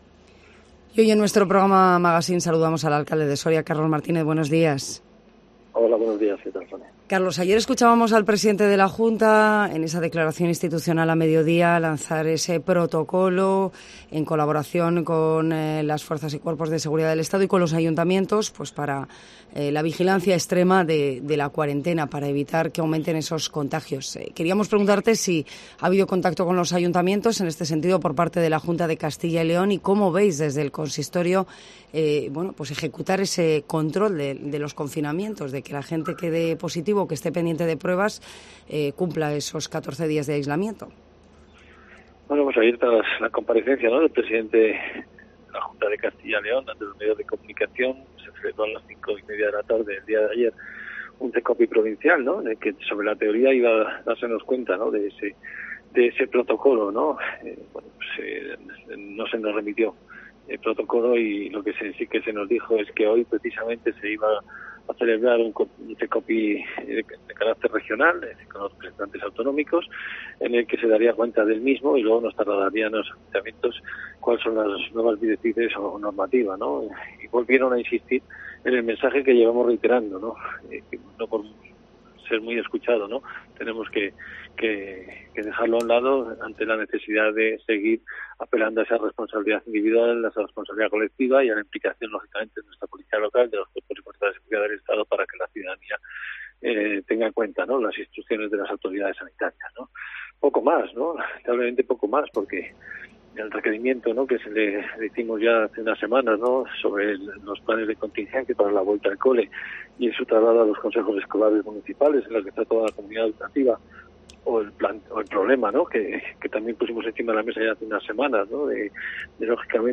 Carlos Martínez habla en Cope Soria de la evolución de la pandemia